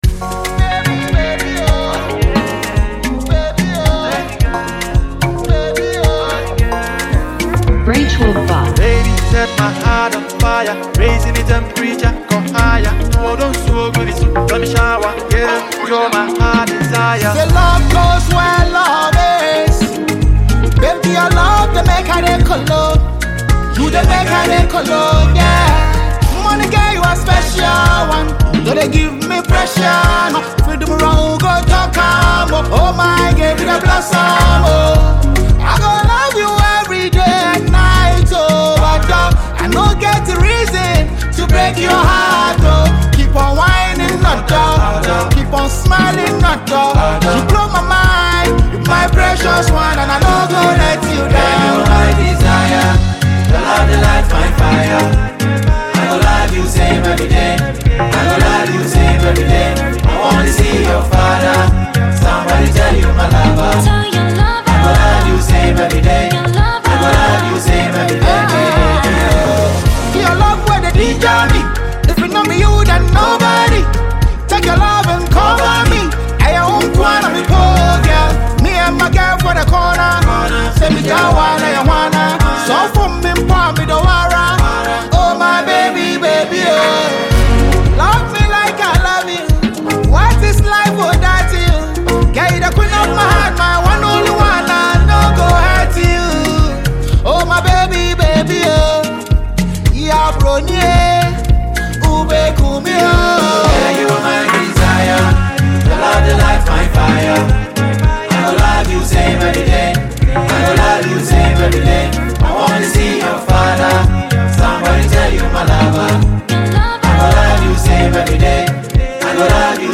catchy new single